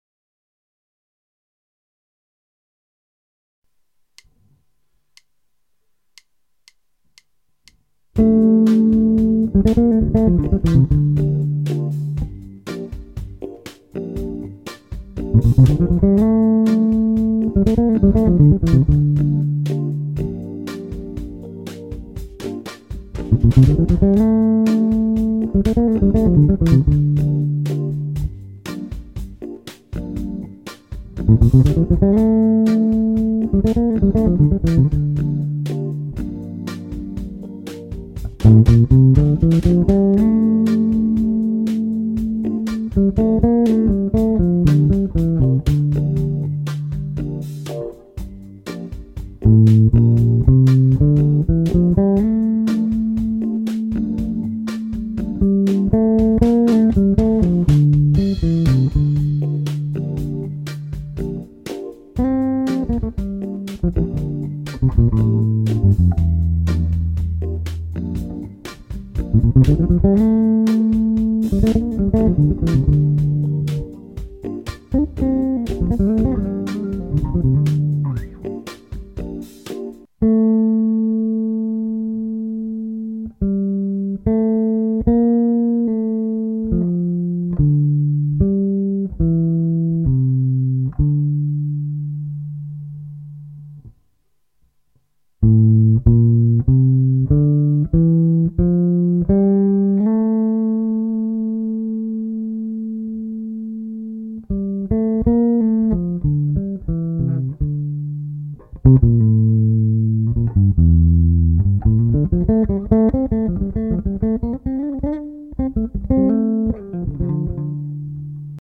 L250 Gm7 dorian bass lick